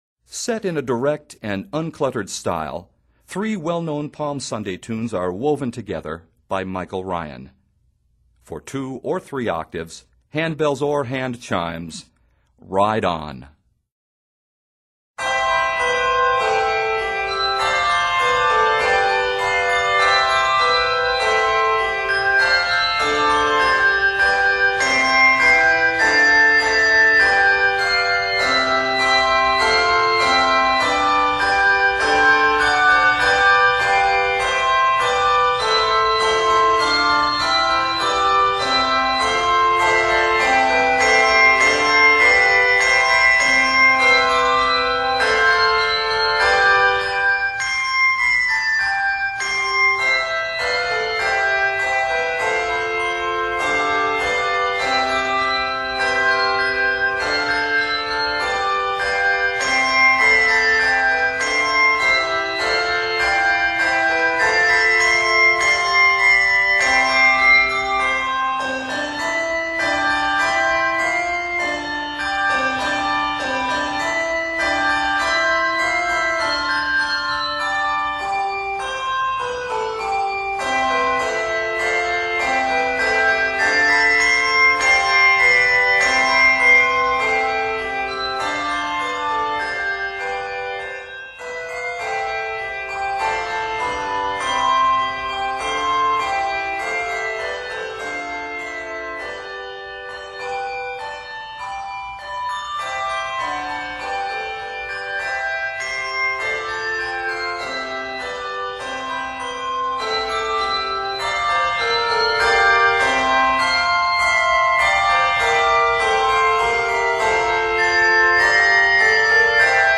Set in a direct and uncluttered style